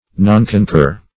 Nonconcur \Non`con*cur"\